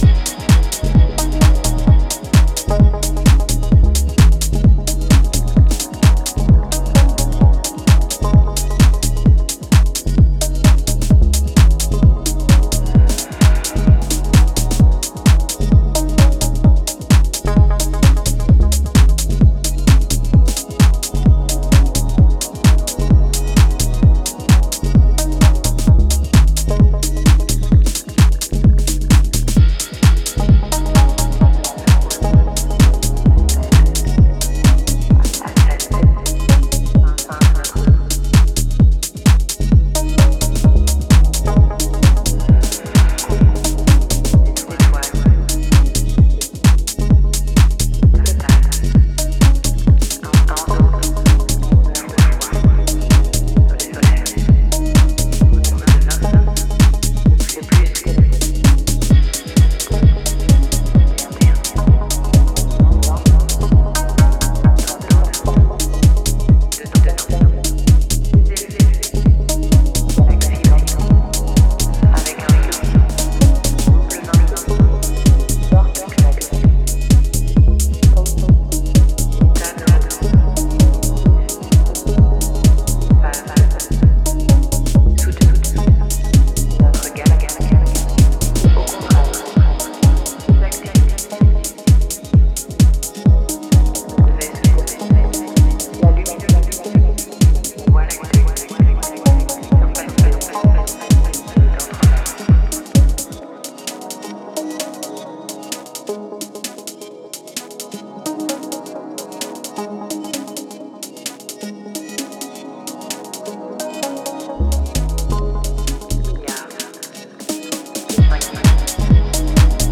ここでは、流麗なシンセワークや推進力溢れるグルーヴを駆使したクールなミニマル・テック・ハウスを展開しています。